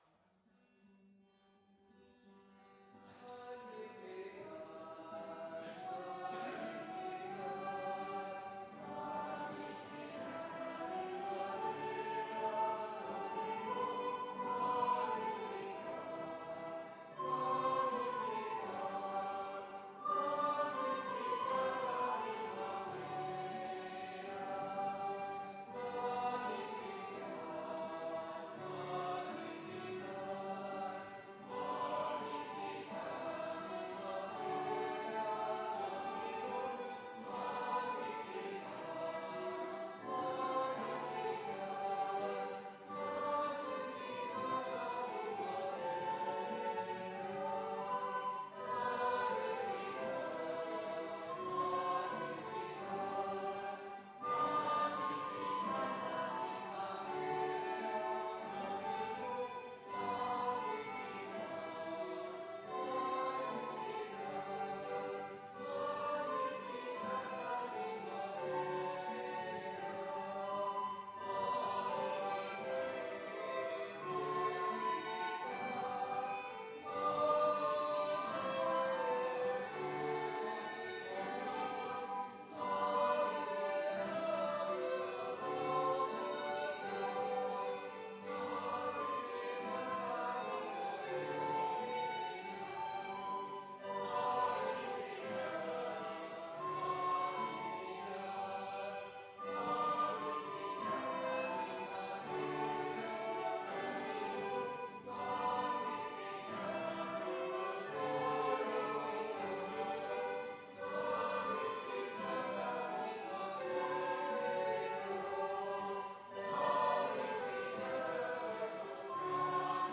Pregària de Taizé a Mataró... des de febrer de 2001
Església de Santa Anna - Diumenge 28 d'octubre de 2018